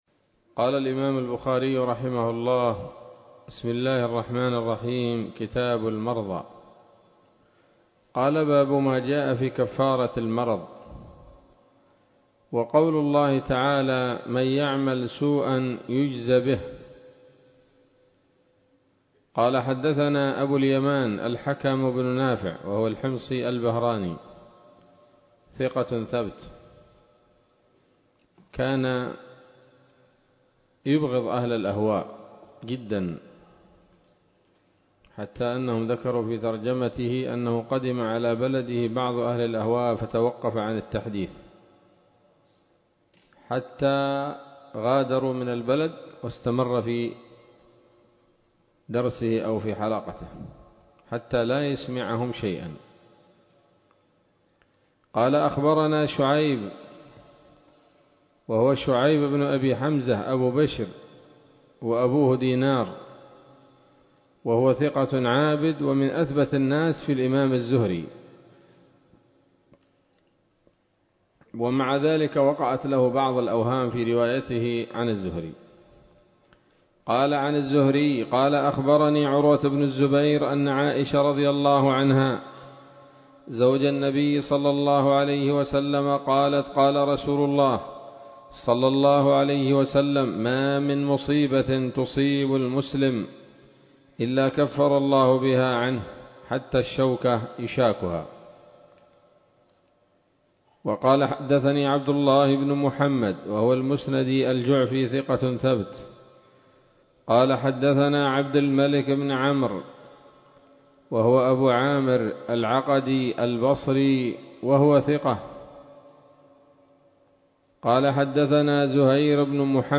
الدرس الأول من كتاب المرضى من صحيح الإمام البخاري